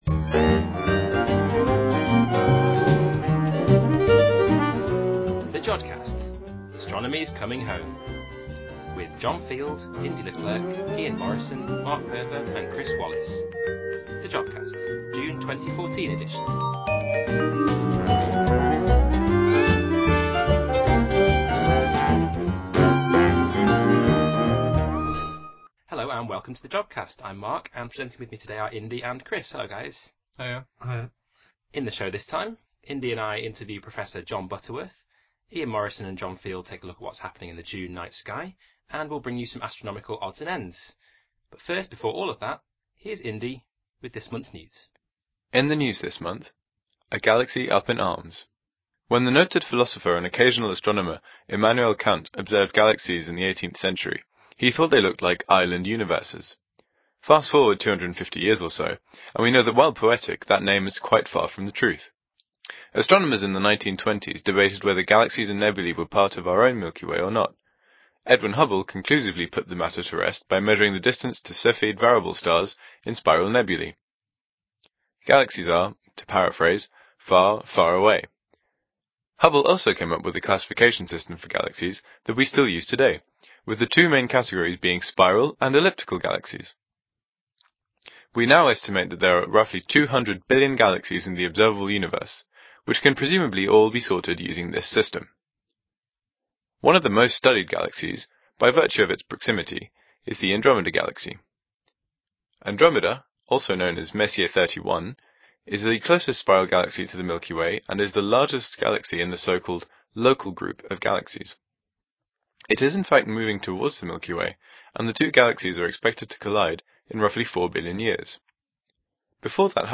Interview with Prof Jon Butterworth